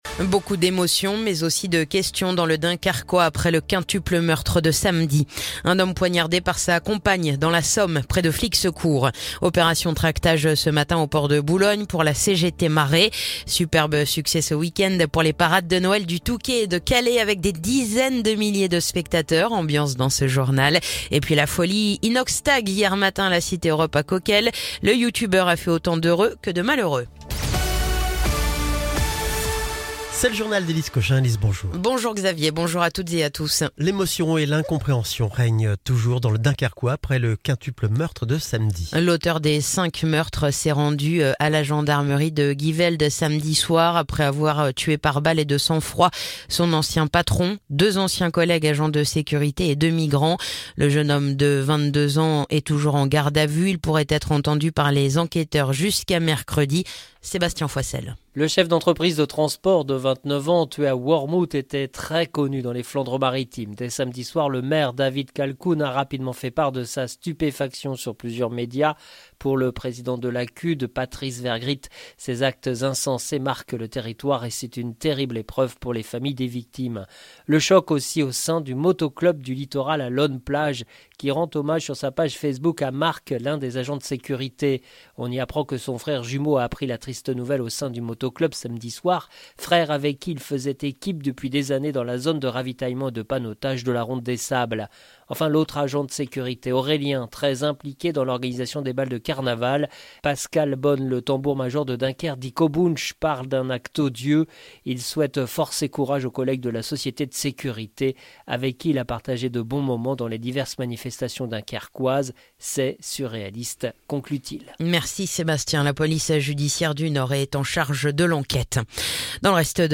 Le journal du lundi 16 décembre